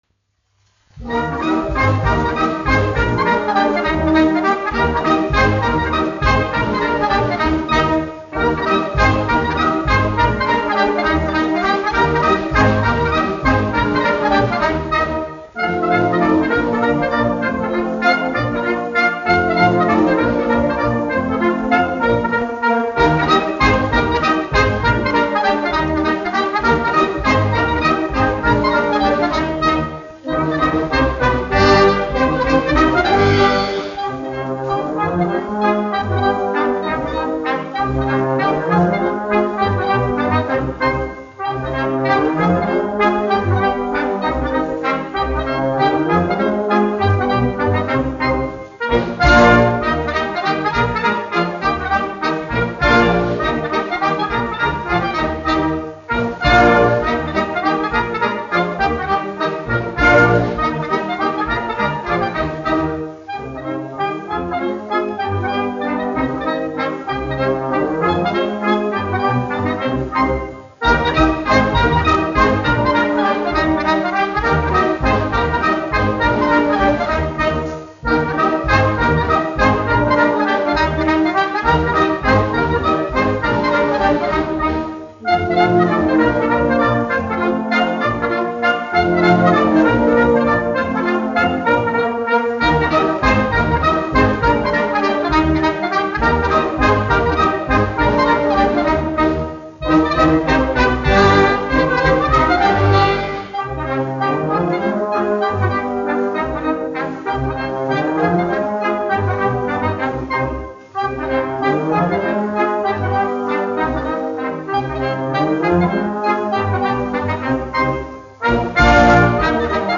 1 skpl. : analogs, 78 apgr/min, mono ; 25 cm
Mazurkas
Pūtēju orķestra mūzika, aranžējumi
Skaņuplate